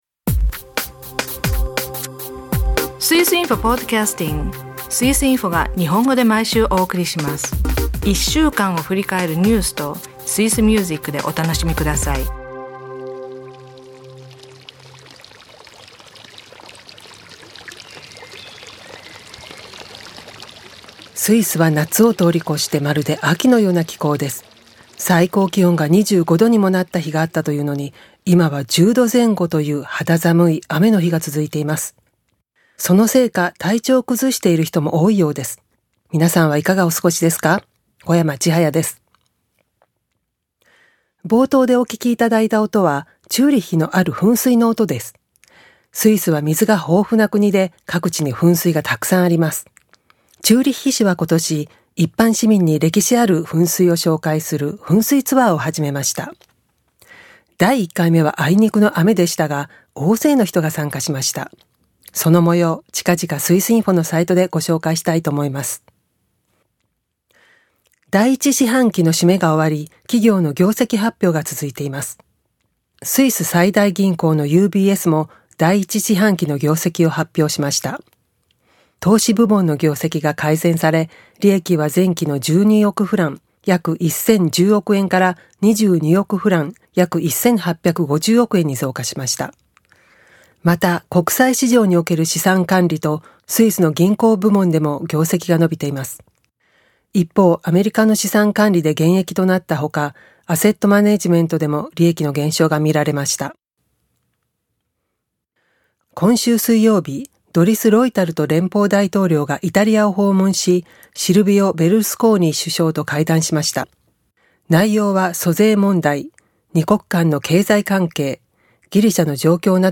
5月8日は「スイス・ヨーデラー連盟」100周年記念の大きな催し物がベルンで開かれます。今週の1曲はその伝統音楽から。5月1日に全国で導入された禁煙、一般市民の反応は？朗読では、ウーリが主人を見つけます。